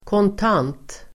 Ladda ner uttalet
Uttal: [kånt'an:t]